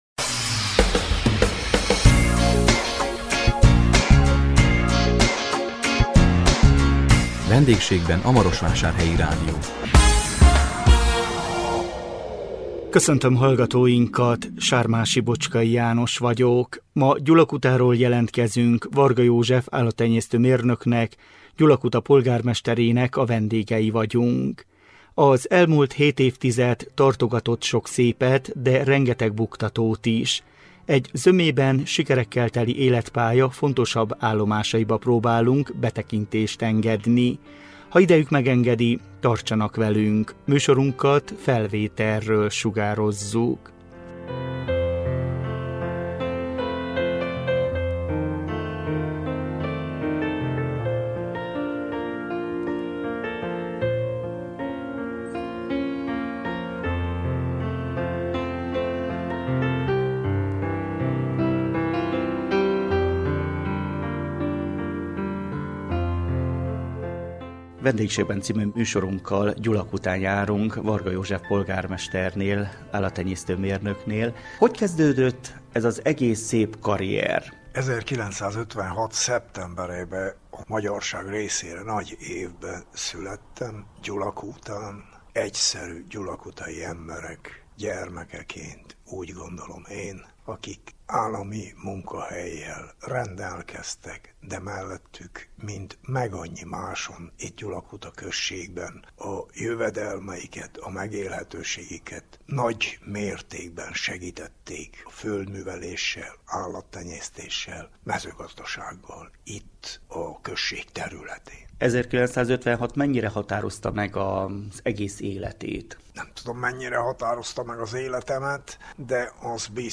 A 2026 március 12-én közvetített VENDÉGSÉGBEN A MAROSVÁSÁRHELYI RÁDIÓ című műsorunkkal Gyulakutáról jelentkeztünk, Varga József állattenyésztő mérnöknek, Gyulakuta polgármesterének a vendégei voltunk.